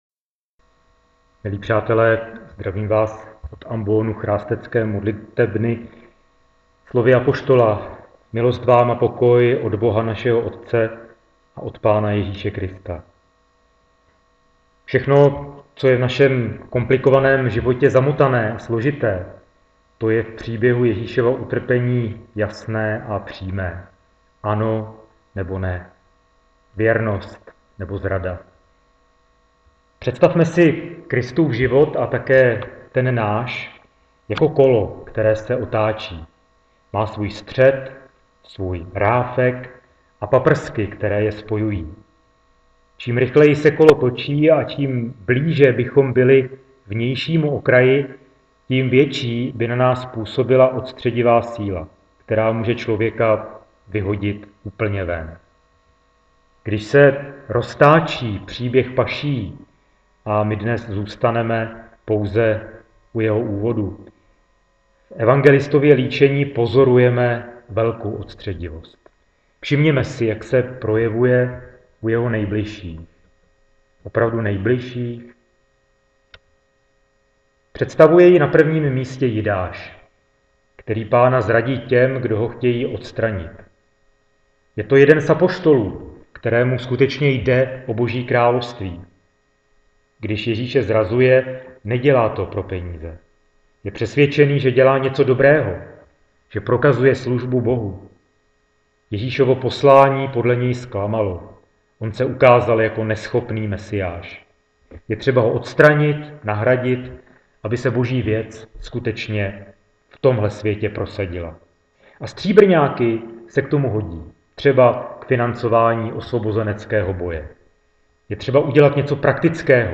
kazani Palmarum.wma